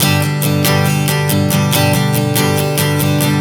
Strum 140 G 03.wav